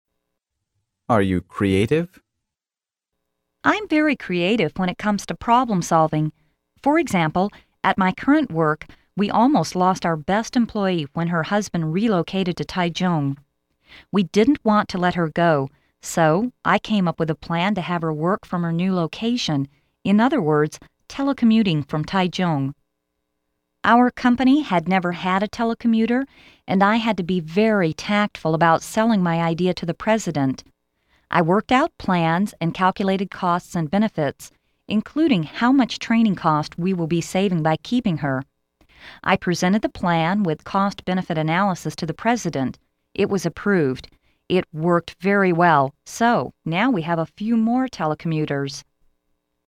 真人朗读，帮助面试者迅速有效优化面试英语所需知识，提高口语能力。